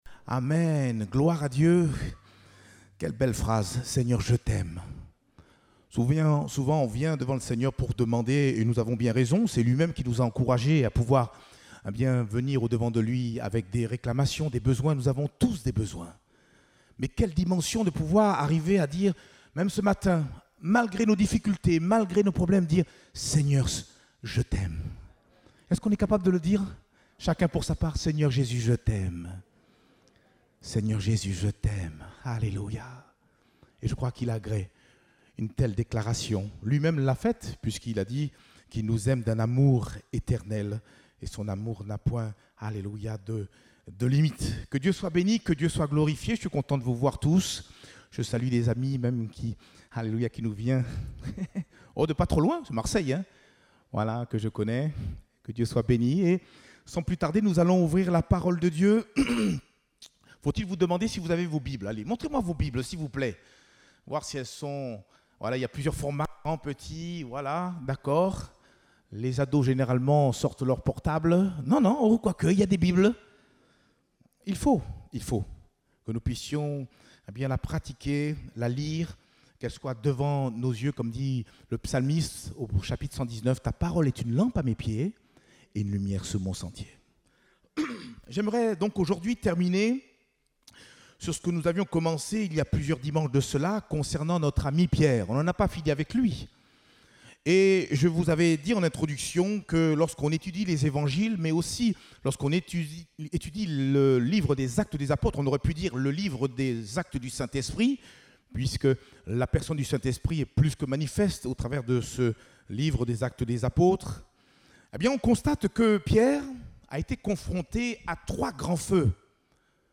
Date : 21 novembre 2021 (Culte Dominical)